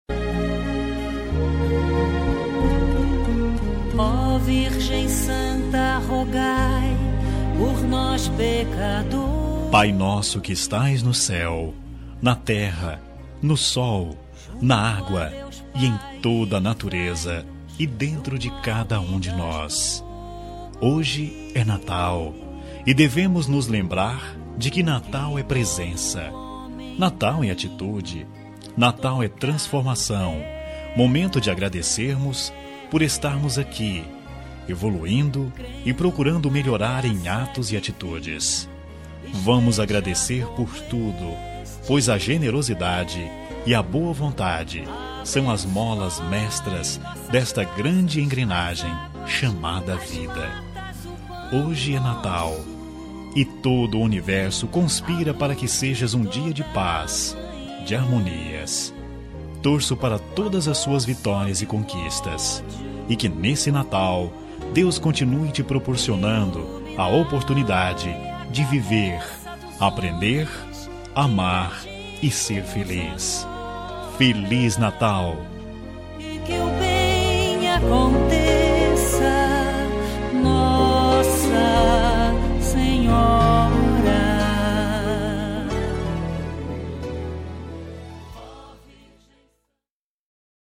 Natal Pessoa Especial – Voz Masculina – Cód: 347921